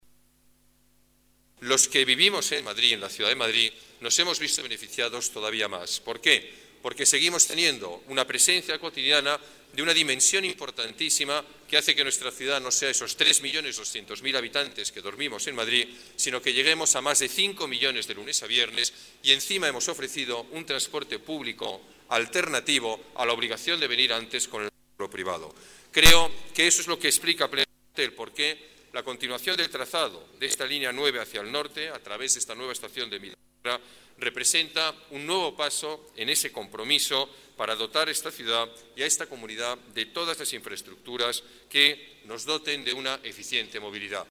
Nueva ventana:Declaraciones del alcalde de la ciudad de Madrid, Alberto Ruiz-Gallardón: Ampliación Línea 9